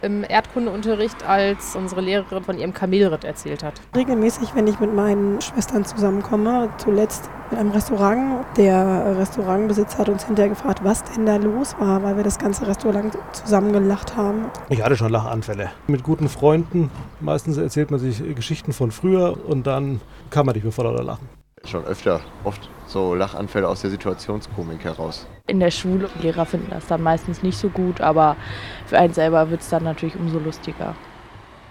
Umfrage - Weltlachtag (5.05.)
umfrage_weltlachtag.mp3